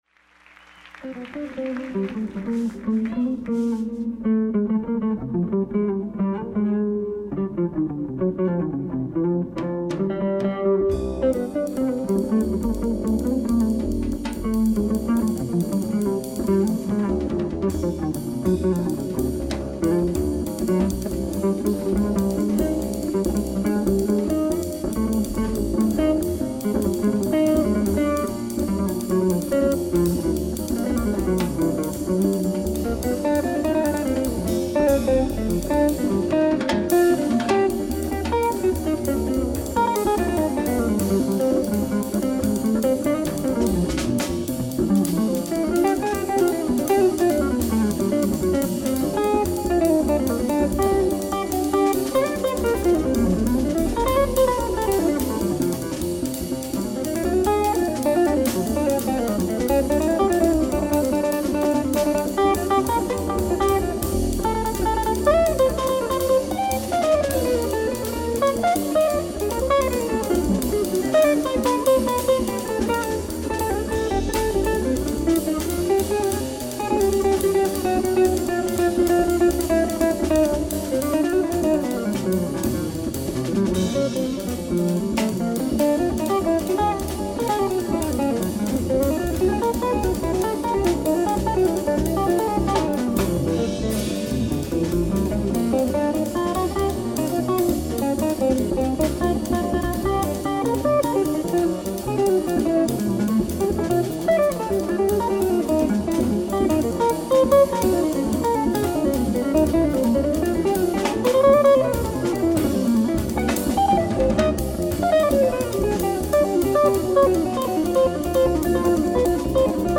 ライブ・アット・シアター・オブ・リビング・アーツ、フィラデルフィア 10/10/1990
※試聴用に実際より音質を落としています。